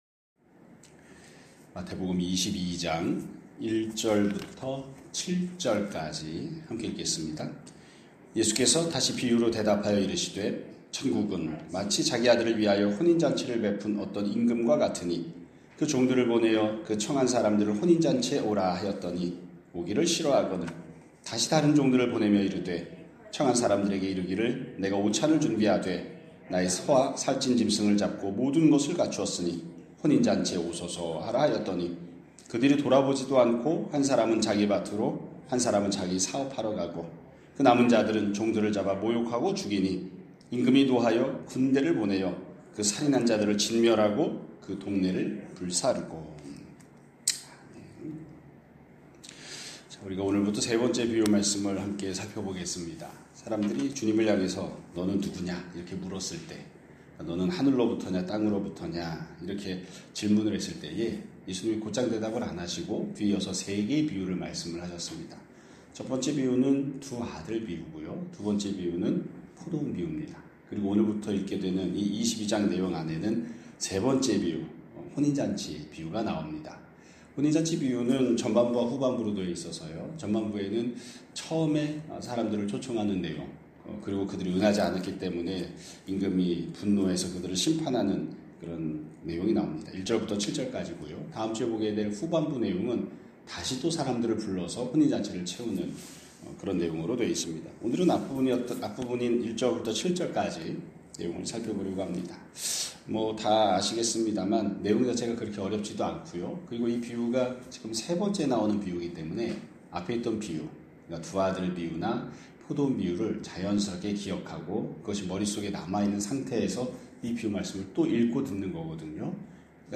2026년 2월 6일 (금요일) <아침예배> 설교입니다.